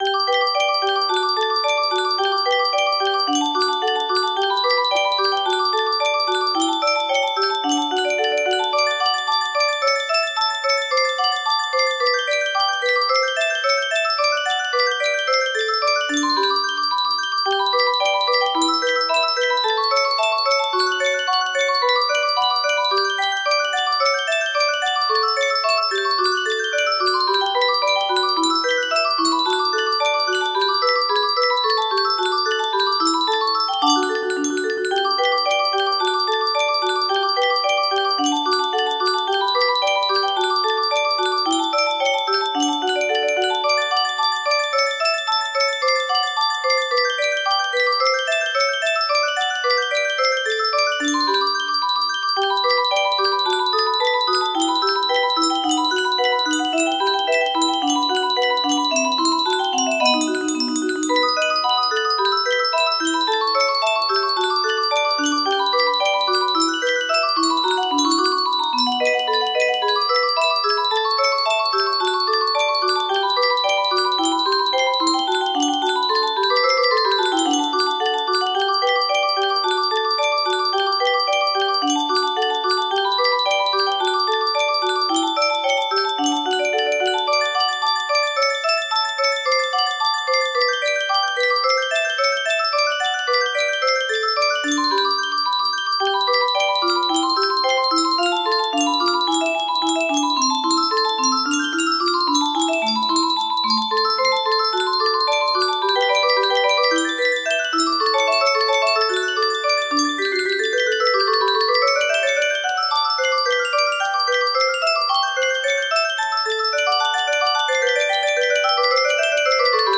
music box melody